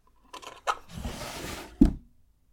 桐タンス引出し開１
op_chest_drawer1.mp3